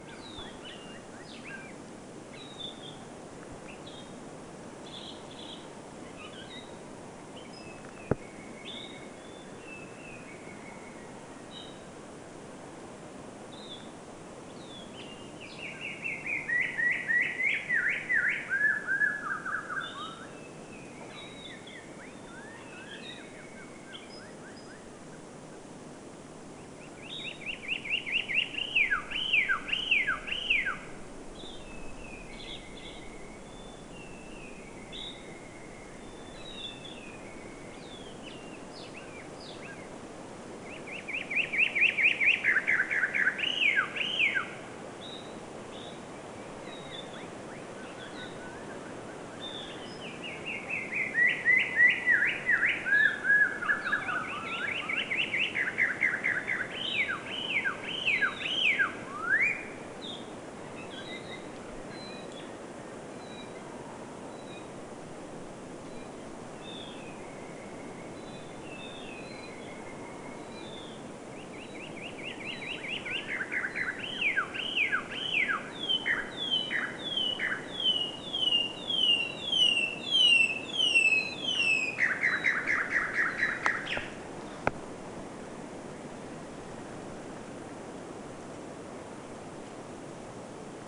puolella nopeudella harmaasiepon laulu
on vähän samankaltaista kuin rastasten laulu
puolella_nopeudella_harmaasiepon_laulu_kuulostaakin_kuin_rastaan_laululta.mp3